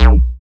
Sound (Dogs).wav